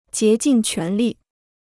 竭尽全力 (jié jìn quán lì) Free Chinese Dictionary